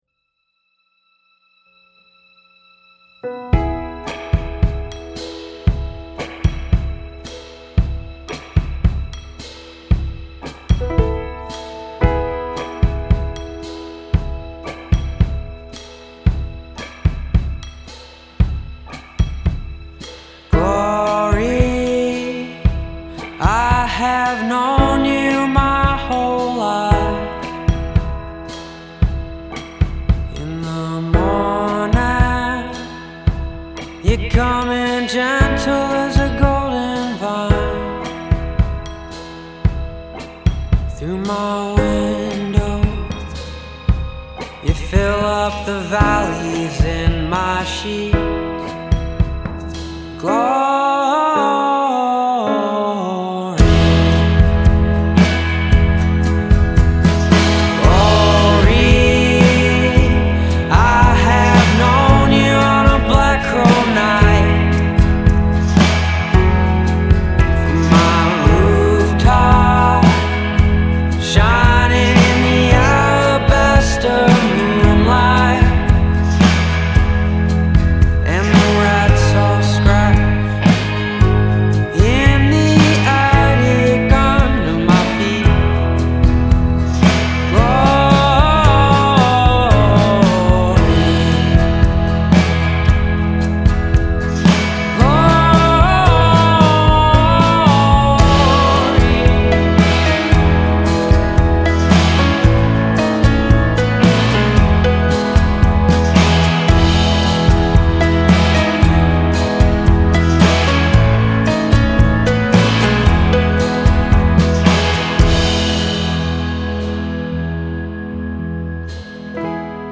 His songs are literate and richly gorgeous.